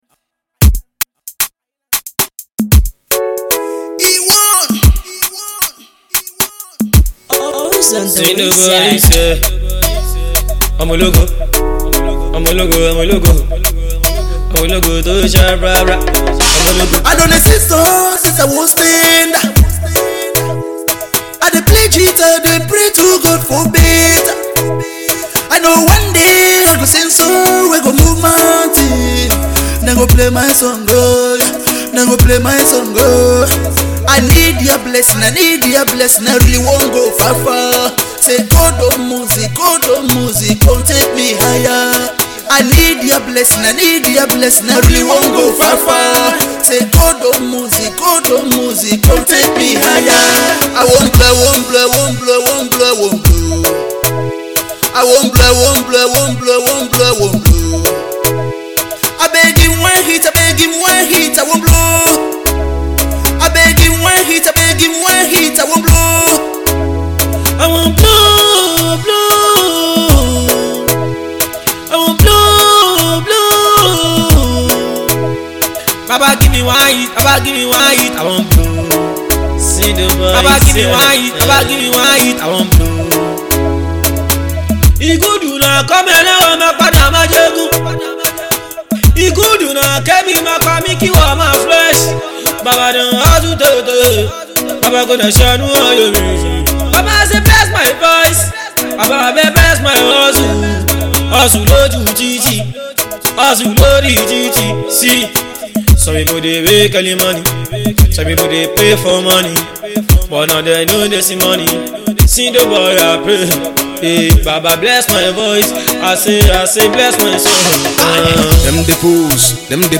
Inline with the Shaku Shaku trend
rap duo
pop act